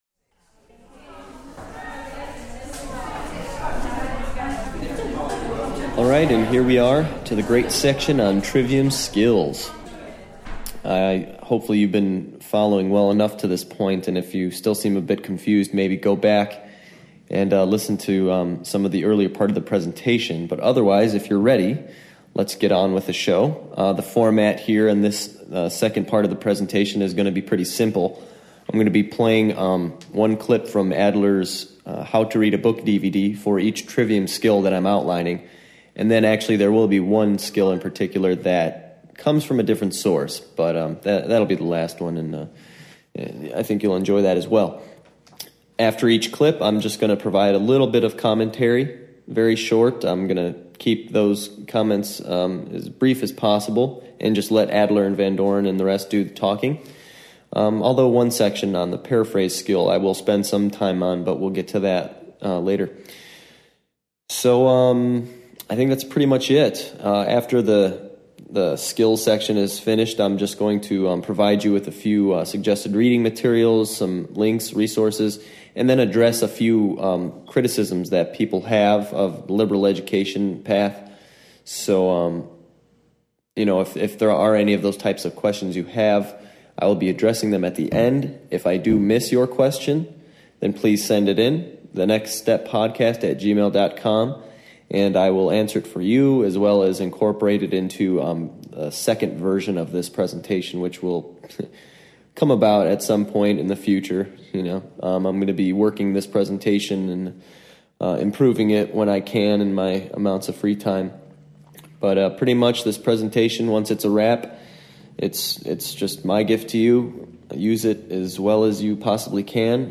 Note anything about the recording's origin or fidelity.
I outline for the audience a few essential skills that are required in order to obtain the most from a Trivium-based education.